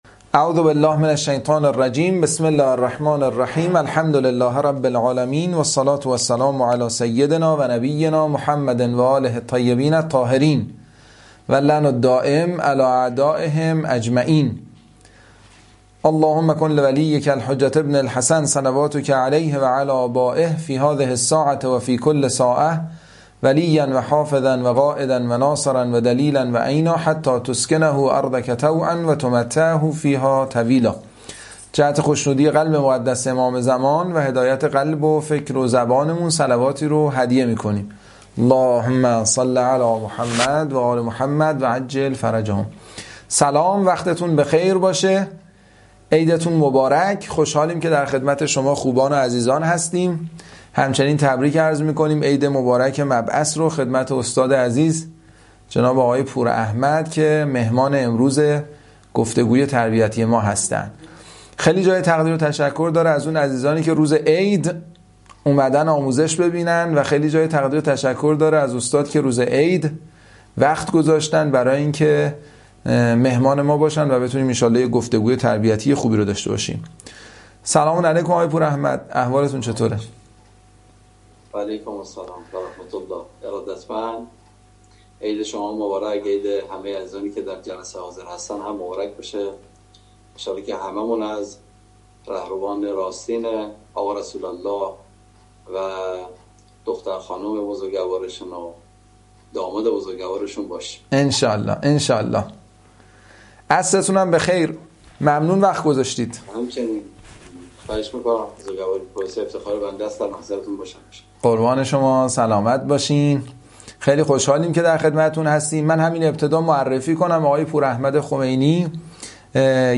یک جلسه دو استاد صوت لایو فایل صوتی